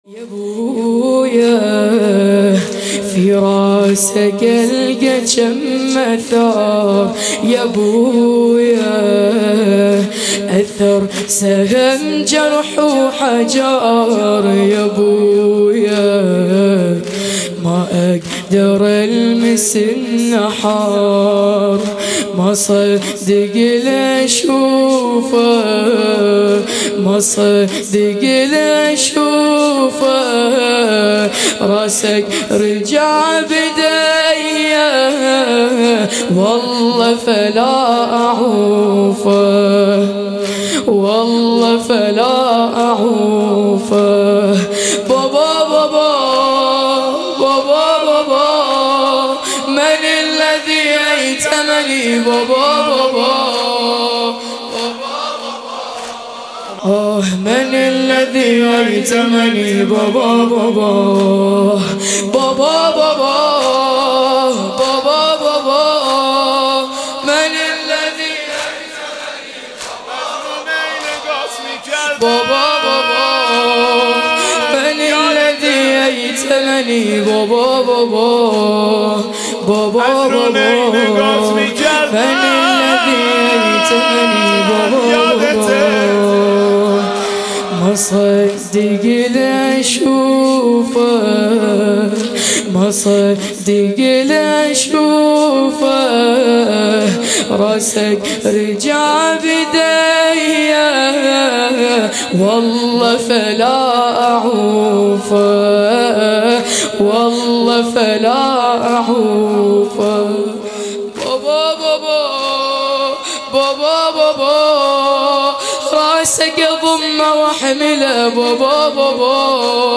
مداحی
جلسه هفتگی